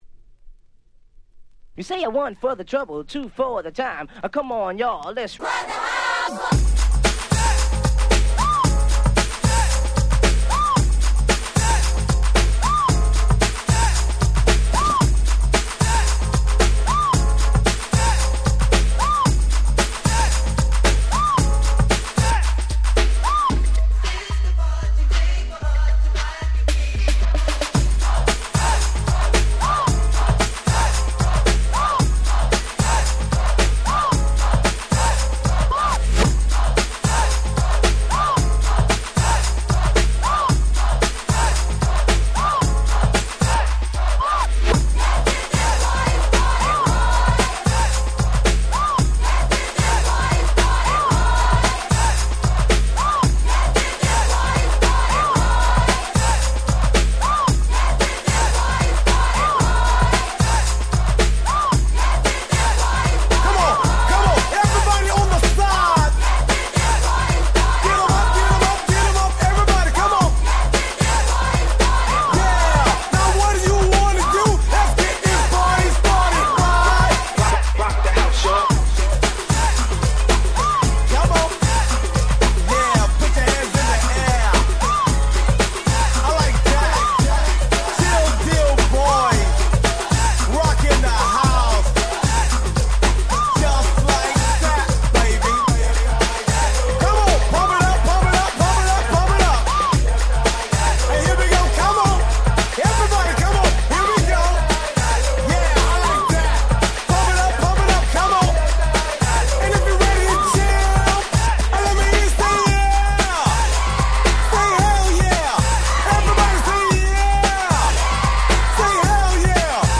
92' Very Nice Hip Hop !!
コールアンドレスポンスなんかも飛び出すアゲアゲな1曲です！！
パーティートラックス Break Beats ブレイクビーツ 90's Boom Bap ブーンバップ